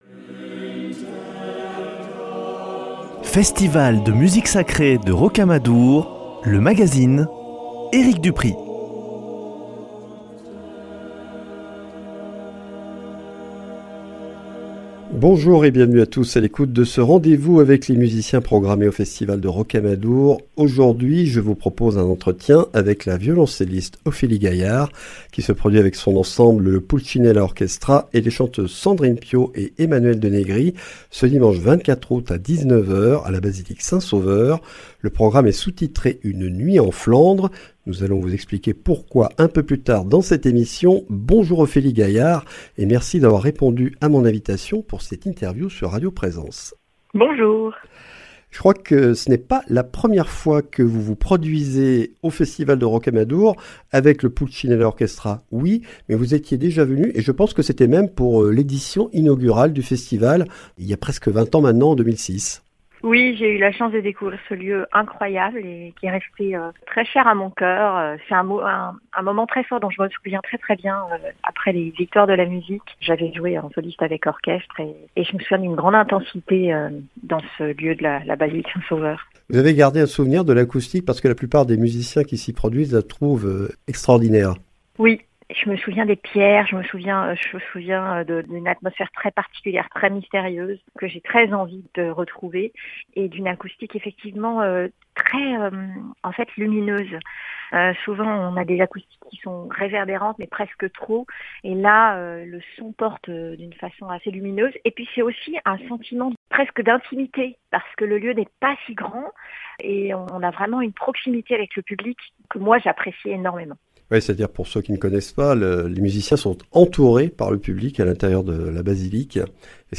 Ophélie Gaillard et l’ensemble Pulcinella, qu’elle a fondé en 2005, accompagnent la soprano Sandrine Piau, dimanche 24 août à 19 h à la basilique St-Sauveur de Rocamadour, dans un programme Une nuit en Flandres. Dans cet entretien, la grande violoncelliste revient sur la création et l’histoire de Pulcinella, son amitié et sa collaboration musicales avec Sandrine Piau, et présente le concert du 24.